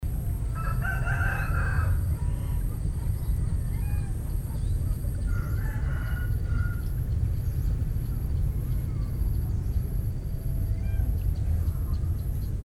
Звуки фермы для малышей